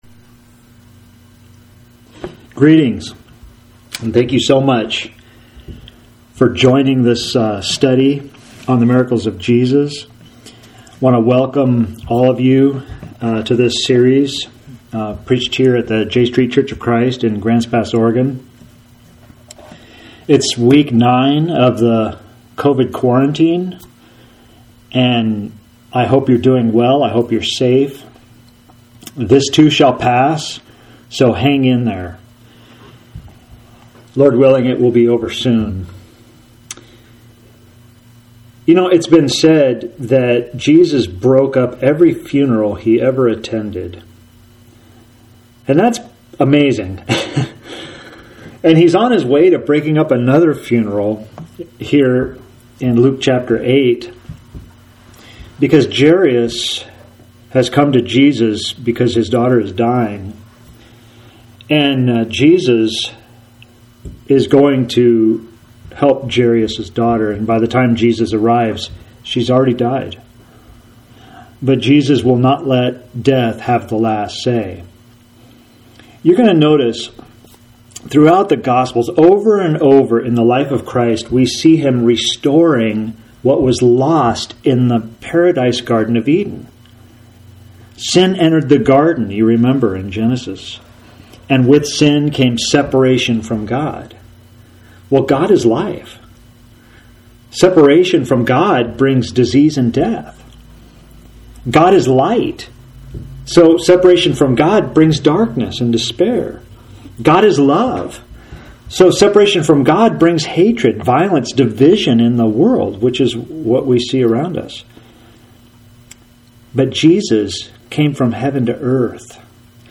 Sermon for May 24, 2020.